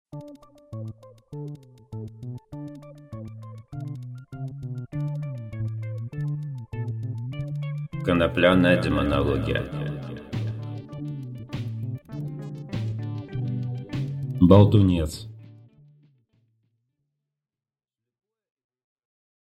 Аудиокнига Болтунец | Библиотека аудиокниг